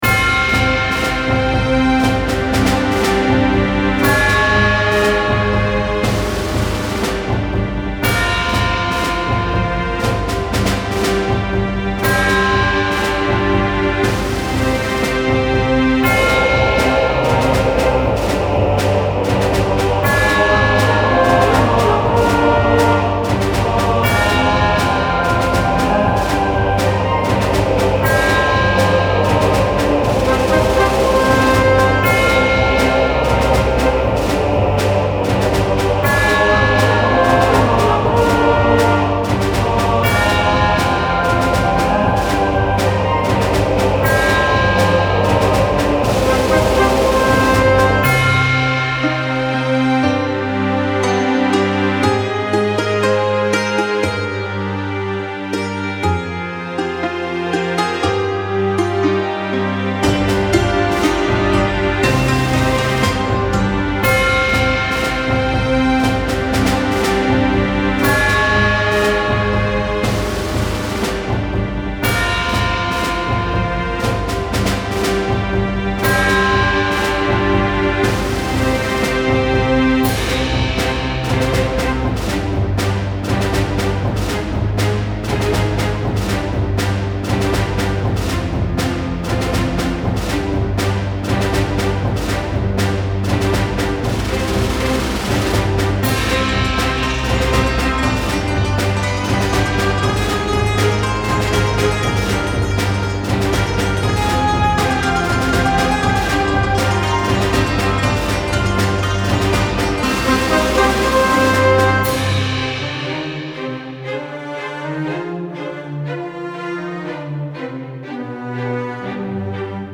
Style Style Soundtrack, World
Mood Mood Uplifting
Featured Featured Bells, Brass, Choir +3 more
BPM BPM 120